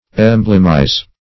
Search Result for " emblemize" : The Collaborative International Dictionary of English v.0.48: Emblemize \Em"blem*ize\, v. t. [imp.